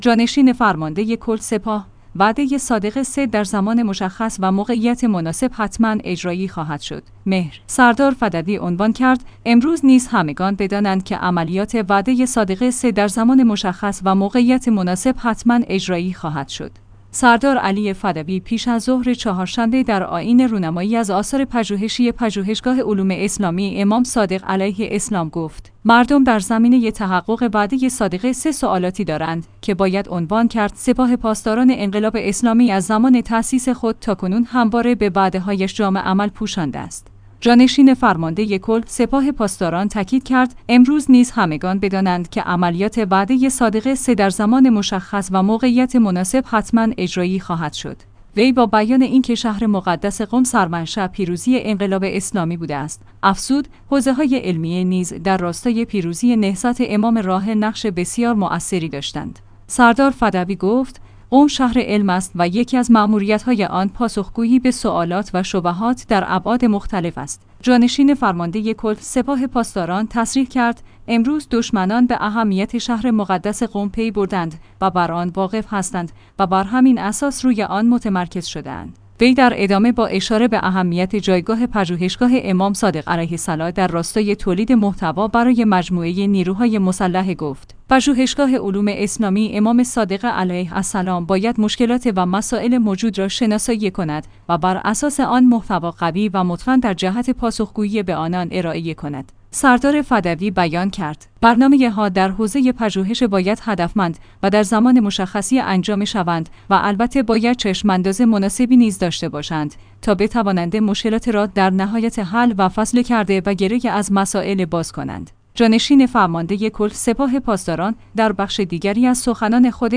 سردار علی فدوی پیش از ظهر چهارشنبه در آئین رونمایی از آثار پژوهشی پژوهشگاه علوم اسلامی امام صادق علیه اسلام گفت: مردم در زمینه تحقق وعده صادق ۳ سوالاتی دارند که باید عنوان کرد س